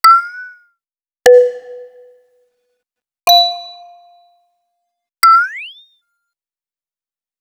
ding.wav